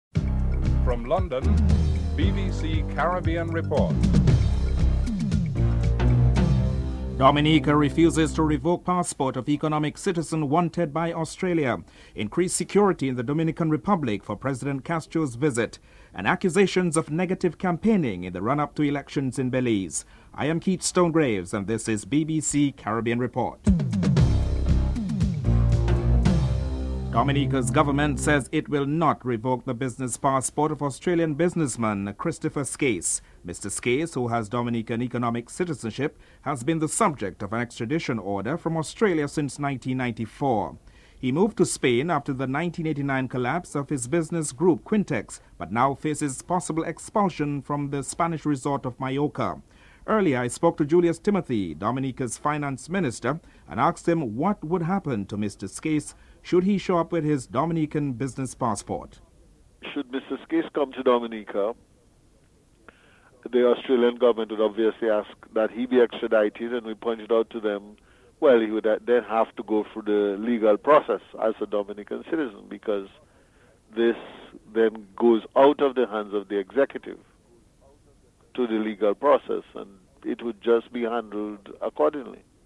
1. Headlines (00:00-00:26)
2. Dominica refuses to revoke passport of economic citizen wanted by Australia. Finance Minister Julius Timothy is interviewed (00:27-03:27)